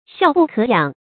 笑不可仰 xiào bù kě yǎng
笑不可仰发音